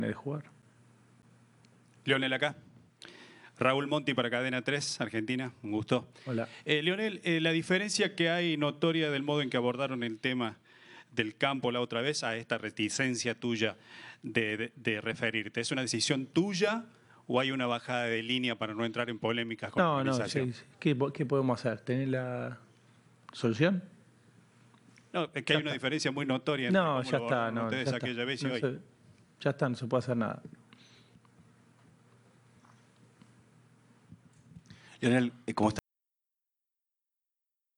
Audio. Lionel Scaloni contestó las preguntas de Cadena 3 en conferencia de prensa